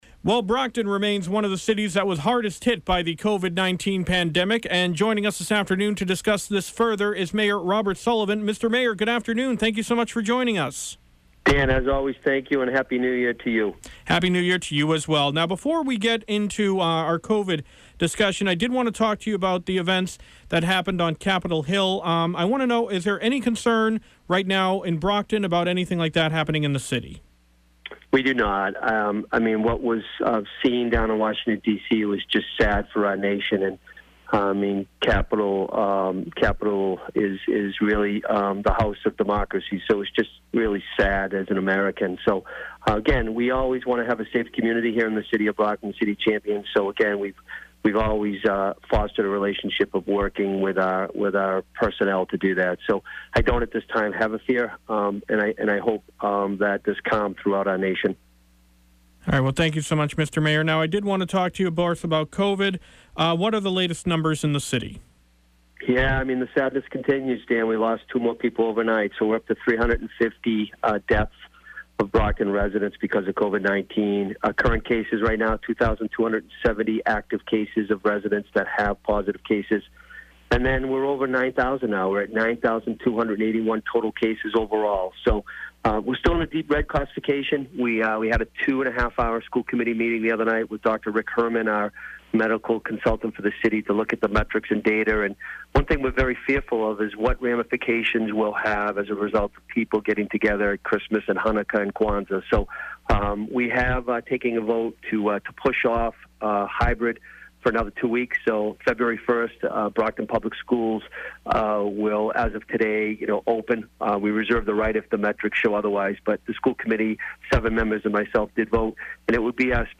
Brockton Mayor Discusses COVID, Storming Of Capitol Building